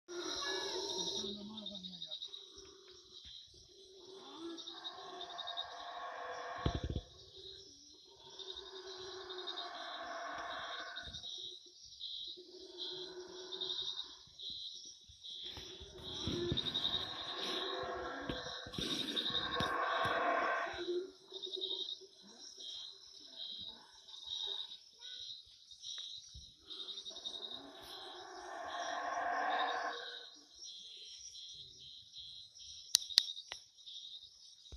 – Kohunlich : des ruines dans une très jolie jungle.
Les singes araignée qui dansent au dessus du camping car laissent la place à des singes hurleurs.